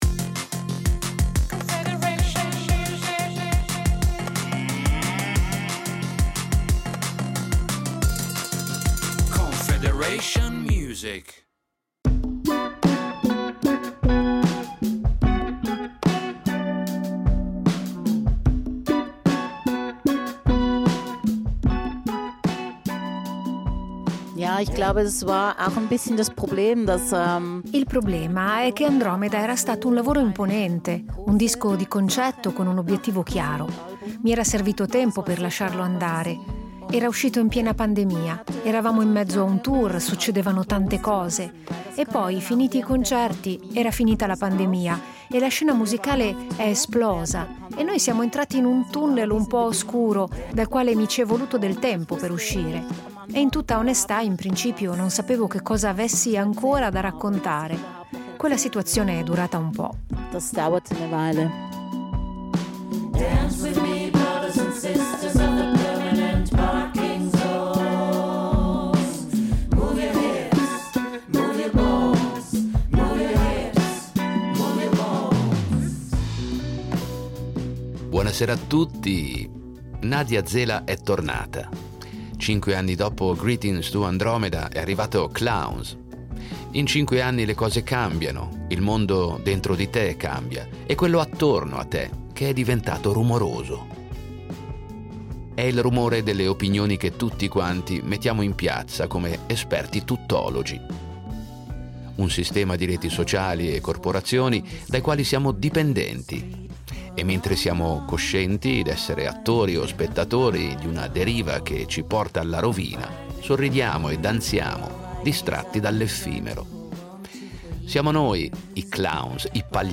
Musica pop
“Clowns” ha un suono più essenziale che racchiude sottigliezze da cogliere. È corale e parlato, un grido musicale per la riconciliazione, per ritrovare, nella tenerezza, la vera libertà.